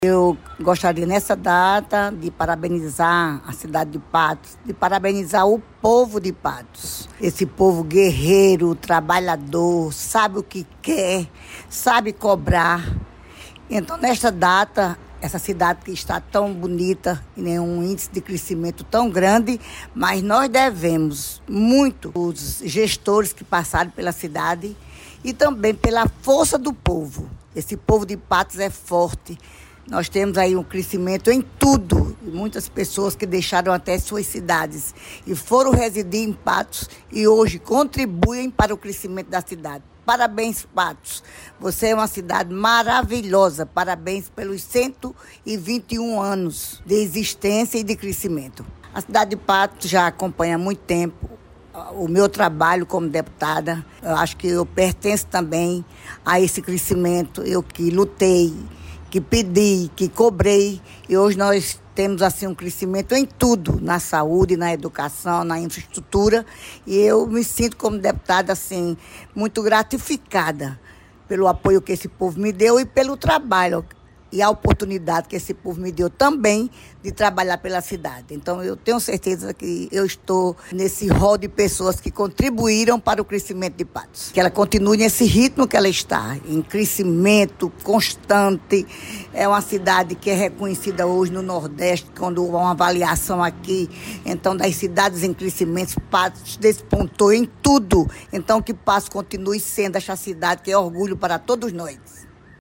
Ouça homenagem: